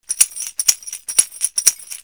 植物繊維の篭、底は瓢箪。小石が入っています。
カシシ大2個